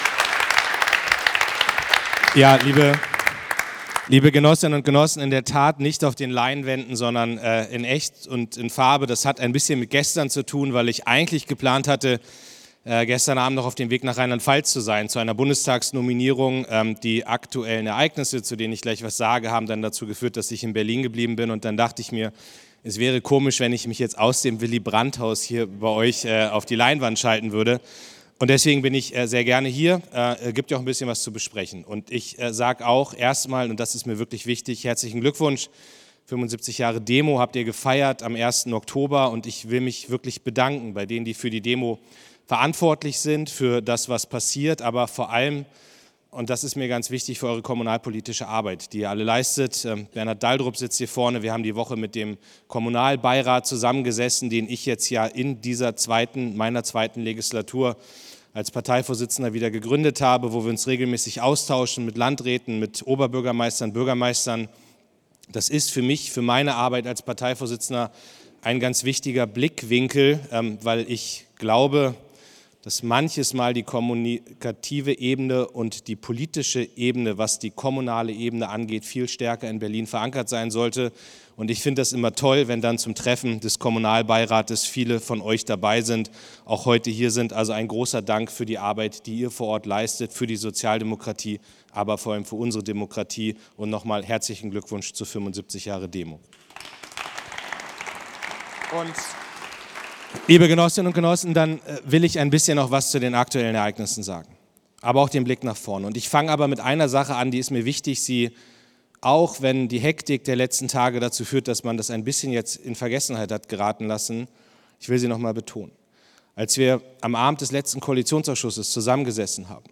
Klingbeil ist zu Gast beim Kommunalkongress der „DEMO“, des kommunalpolitischen Schwestermagazin des „vorwärts“.
(Hier gibt es die Rede zum Nachhören.)
Rede-Klingbeil-Demo-Kongress.mp3